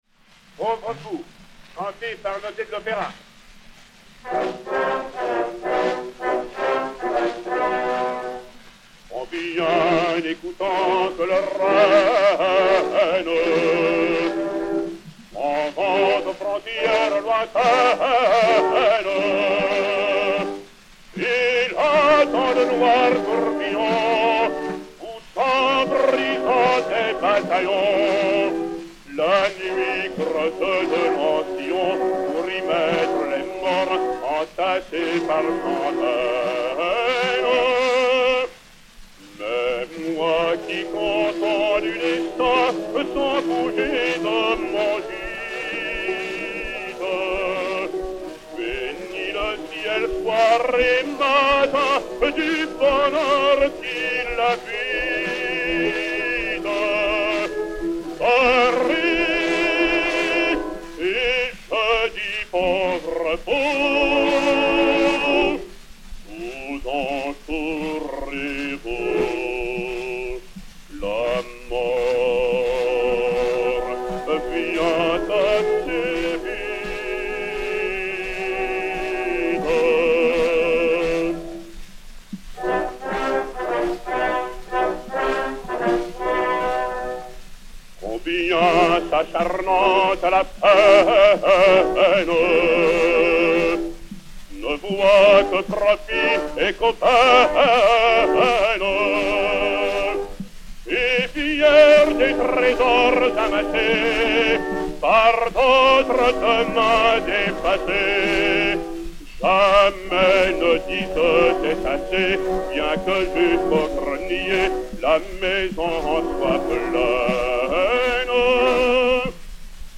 Jean Noté et Orchestre